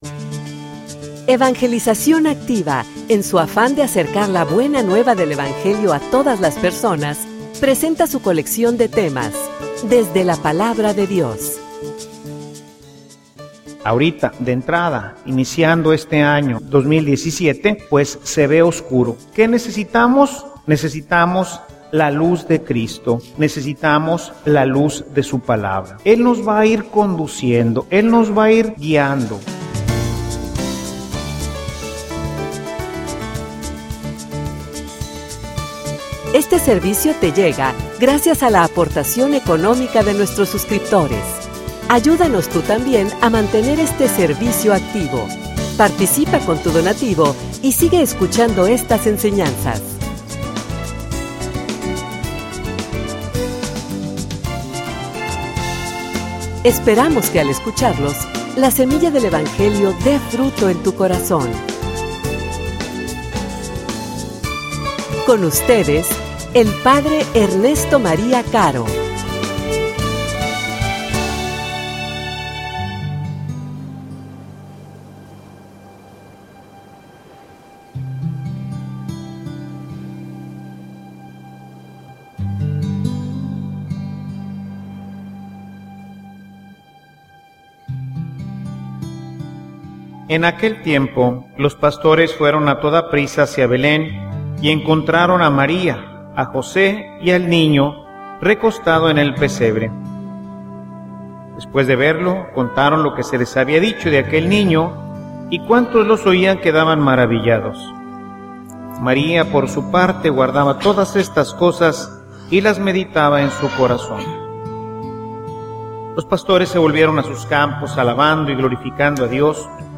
homilia_No_vivan_con_miedo.mp3